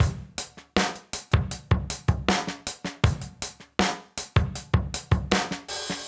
Index of /musicradar/sampled-funk-soul-samples/79bpm/Beats
SSF_DrumsProc2_79-04.wav